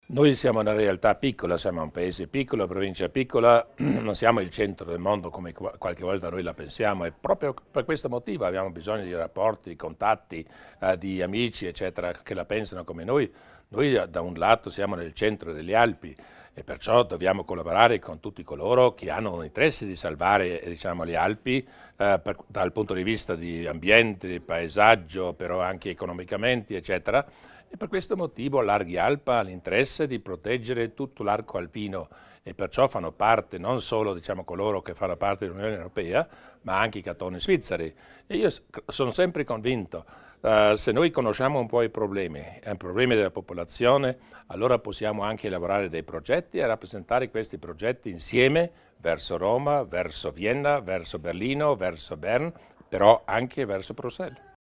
Il Presidente Durnwalder spiega l'importanza di Arge Alp